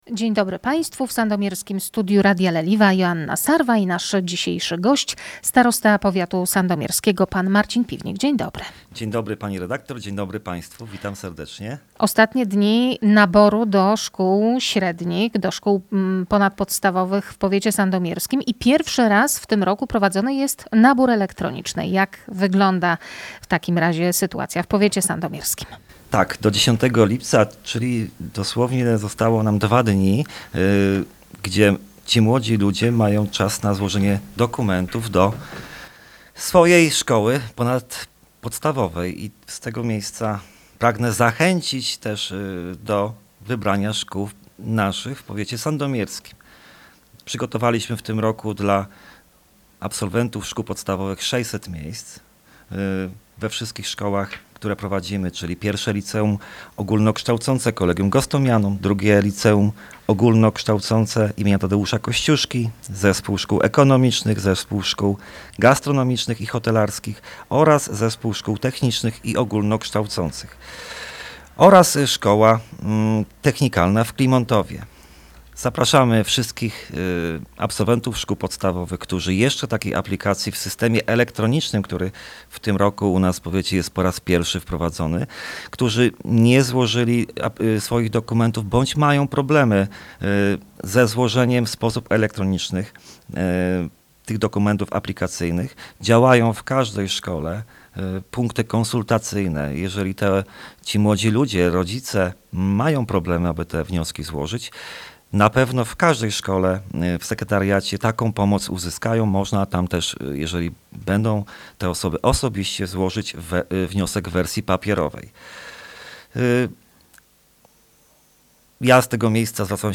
O naborze elektronicznym do szkół mówi starosta sandomierski Marcin Piwnik.